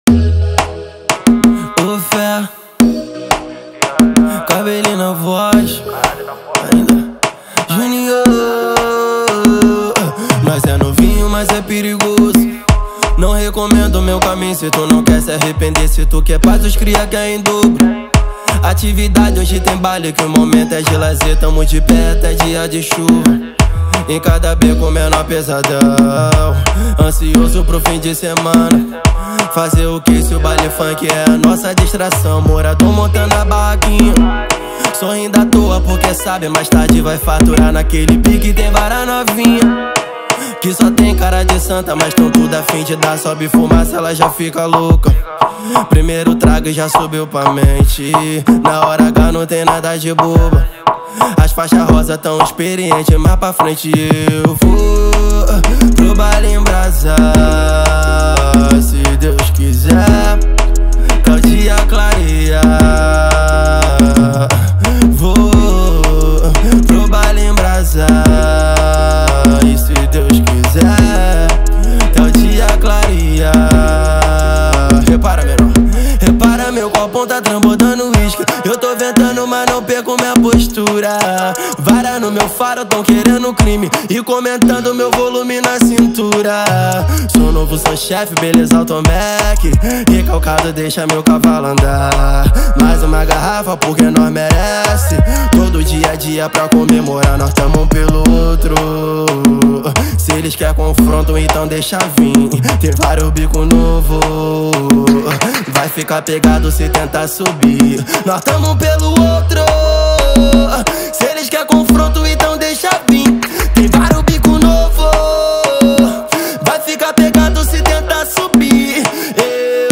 2024-03-03 10:02:03 Gênero: Trap Views